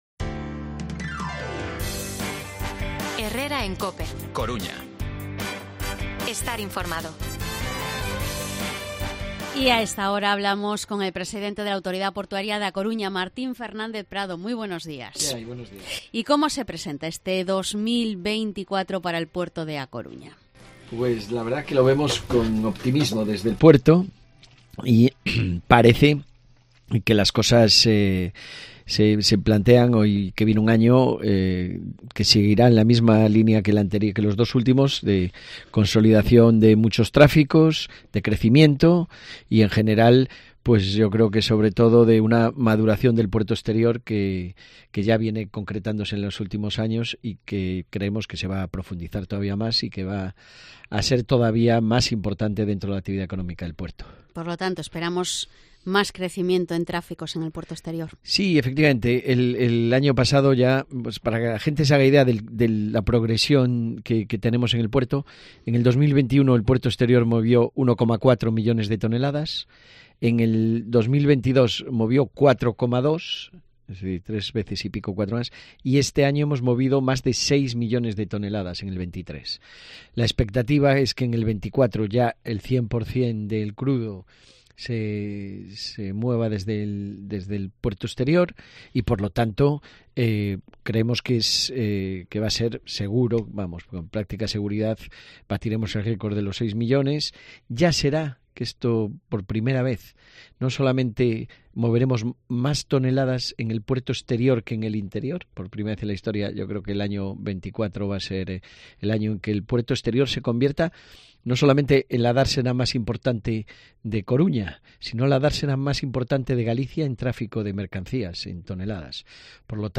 Entrevista al presidente de la Autoridad Portuaria de A Coruña, Martín Fernández Prado